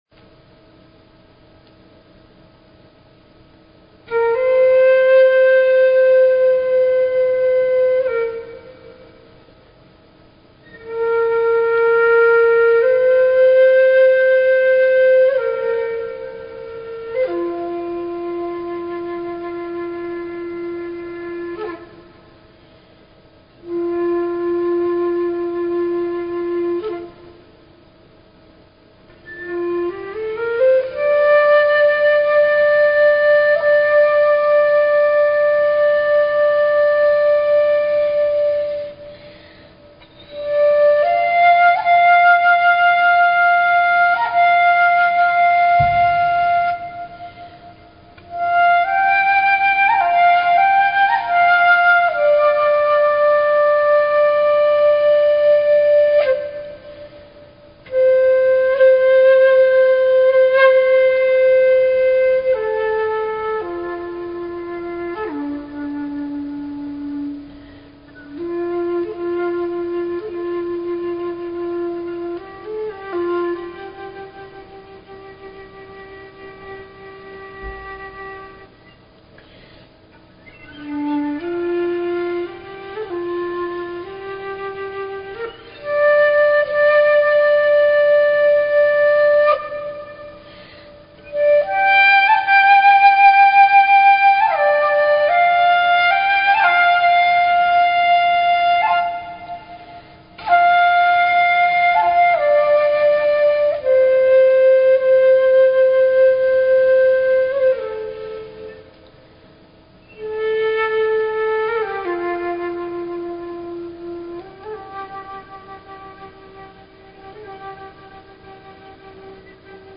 Talk Show Episode, Audio Podcast
We CALL IN THE LIGHT and listen to channeled messages from the Elohim!